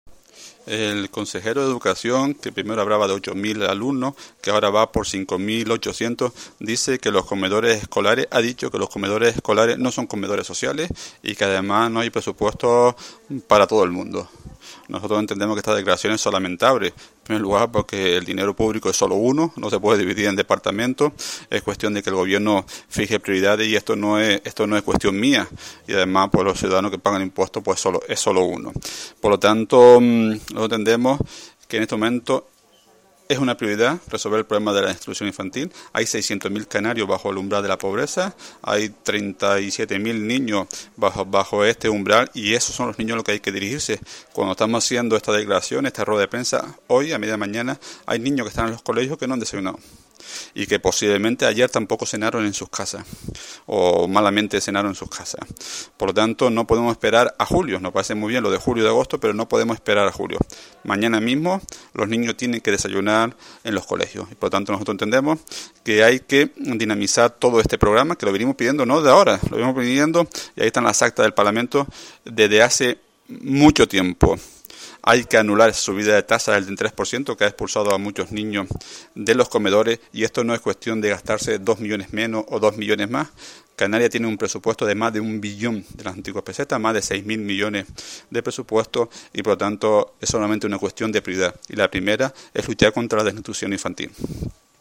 Oñate, que compareció en rueda de prensa junto al diputado Felipe Afonso, censuró que el presidente del Gobierno “haya montado un espectáculo bochornoso a costa de la tragedia de miles de familias canarias, un espectáculo que no tiene como objetivo solucionar el problema, sino distraer la atención y eludir su responsabilidad”.
FELIPE_AFONSO.mp3